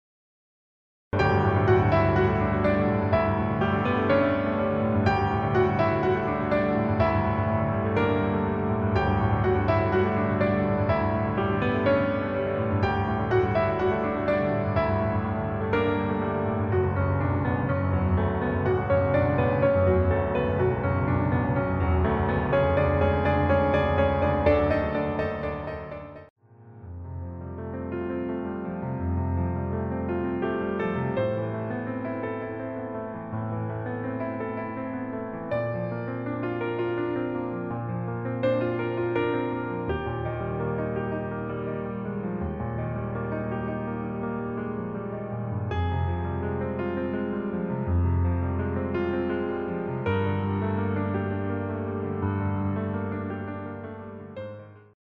Geburtstags des Amigas ein weiteres Klavierkonzert geben (